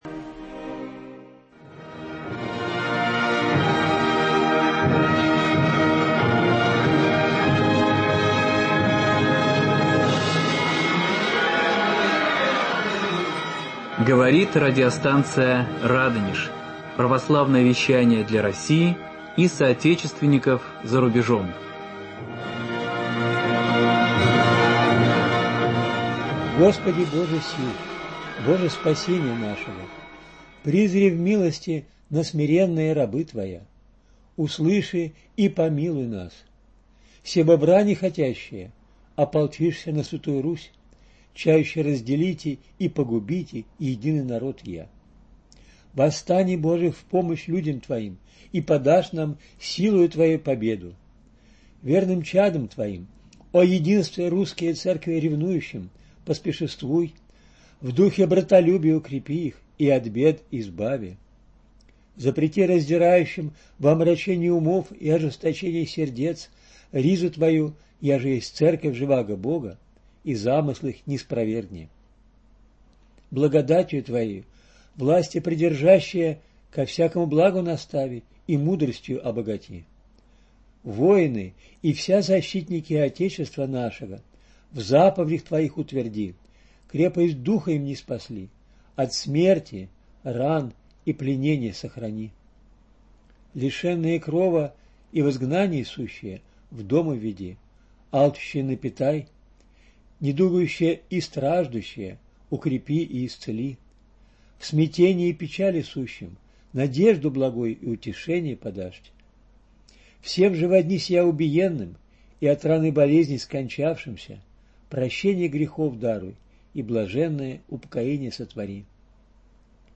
Голоса прошлого: воспоминания представителей русской интеллигенции, записанные в 60-е годы прошлого века.